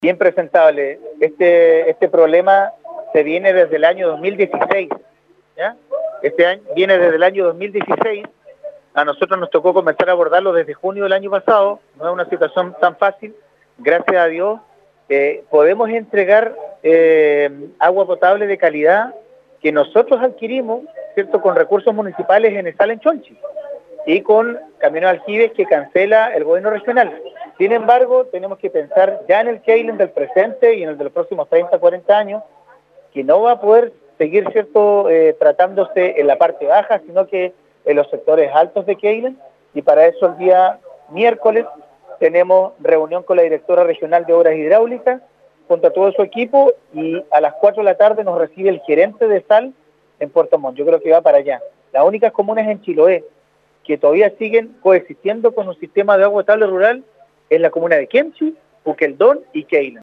El edil de Queilen manifestó que el problema se arrastra desde 2016 y que hay entrega de emergencia para los vecinos, por medio de estanques distribuidos en ciertos puntos o repartida en camiones aljibes.